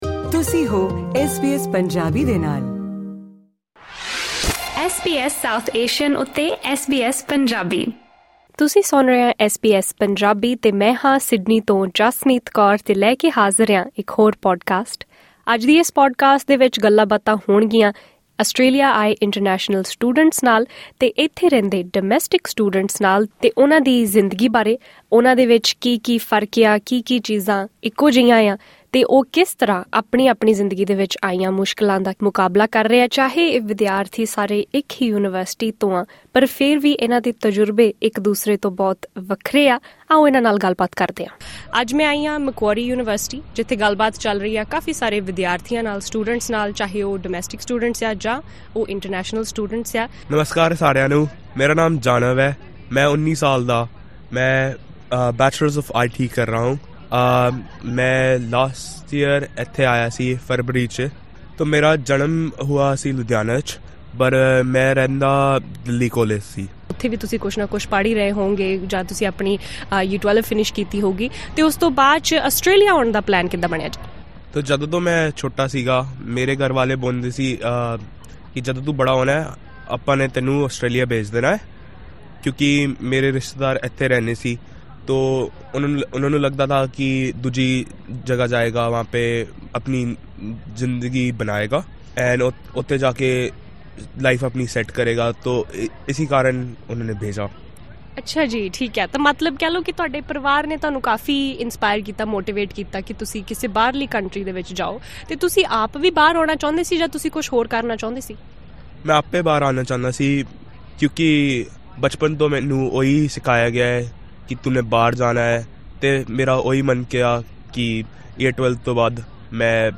SBS Punjabi visited Macquarie University during the 'Kickstart week' —the orientation period before the start of Semester Two—and spoke with Punjabi-speaking international and domestic students.